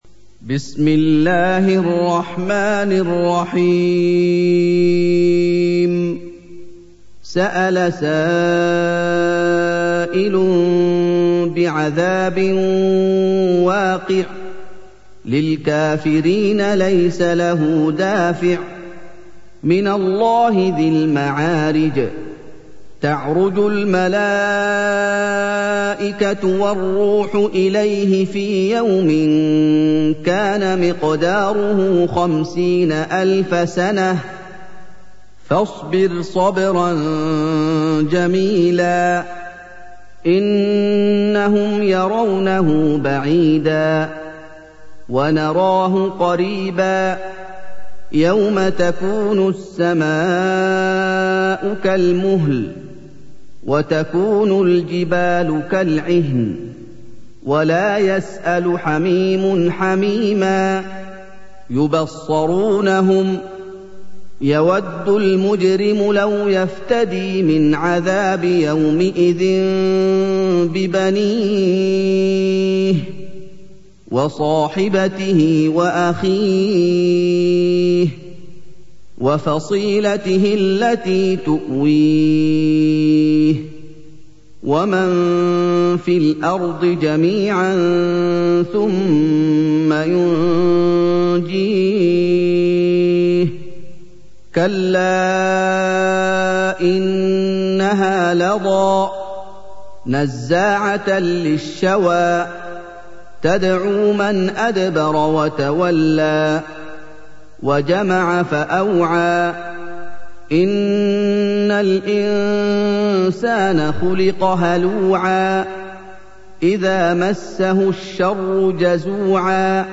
سُورَةُ المَعَارِجِ بصوت الشيخ محمد ايوب